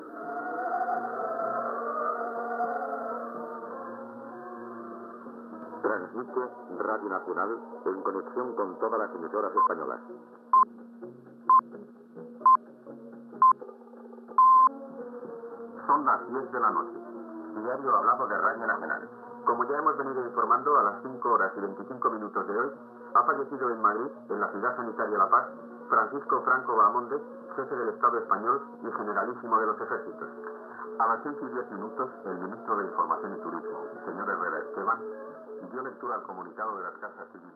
Informatiu especial de les 22 hores.
Informatiu